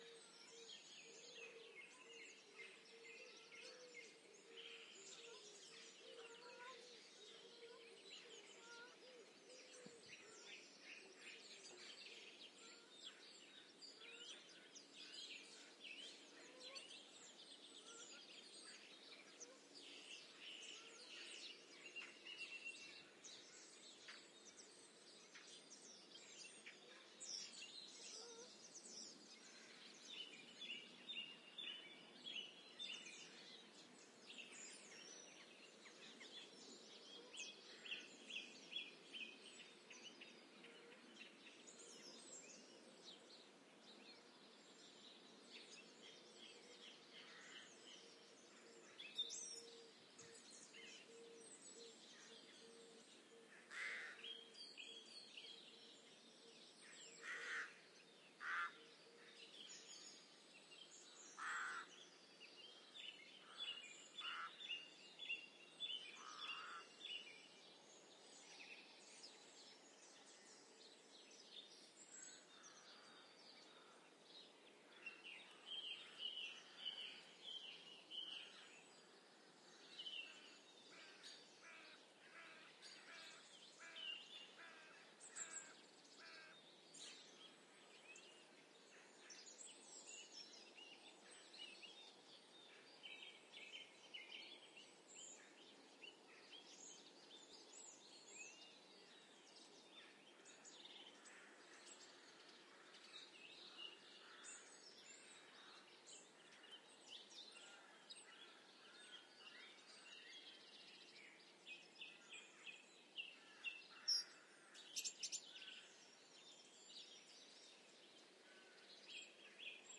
气氛 " EXT 乡村多塞特 1
描述：在英格兰东南部的一个农场里录制的立体声气氛。用zoom h4n不幸地录制。
Tag: 农村 ATMOS 农场 自然 大气